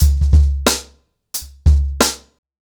TrackBack-90BPM.23.wav